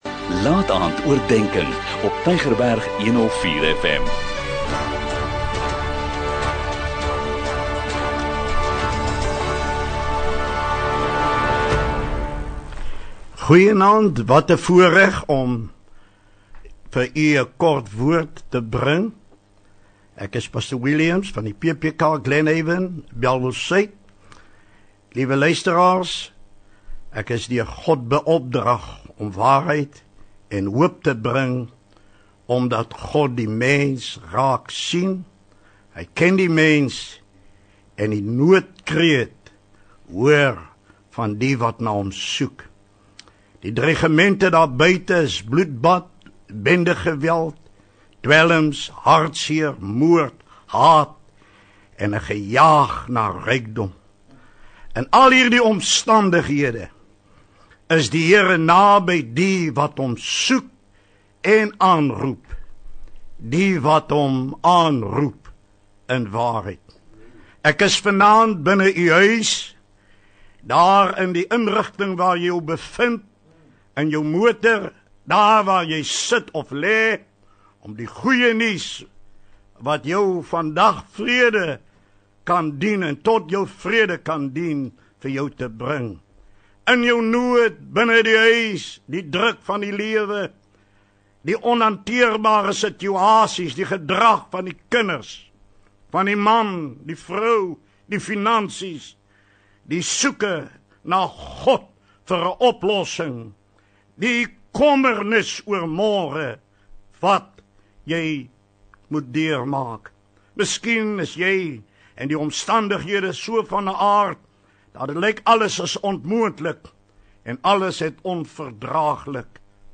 'n Kort bemoedigende boodskap, elke Sondagaand om 20:45, aangebied deur verskeie predikers